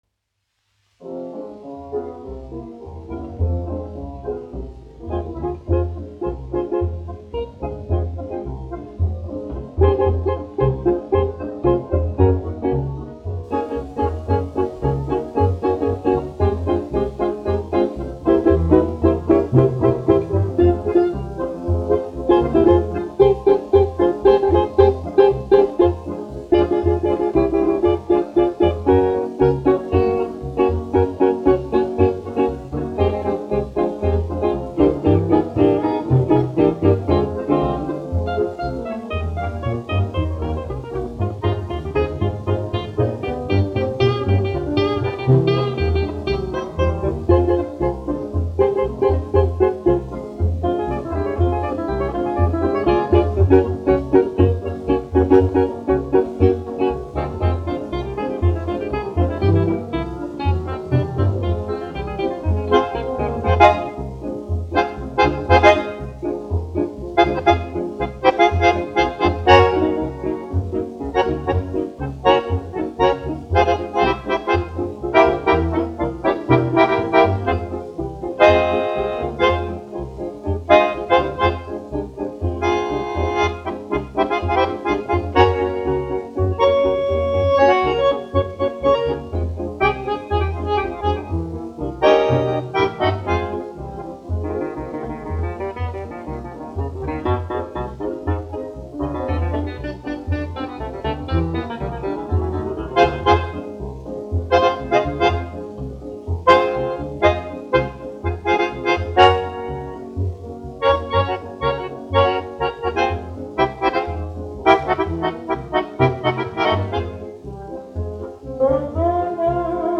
1 skpl. : analogs, 78 apgr/min, mono ; 25 cm
Populārā instrumentālā mūzika
Fokstroti
Latvijas vēsturiskie šellaka skaņuplašu ieraksti (Kolekcija)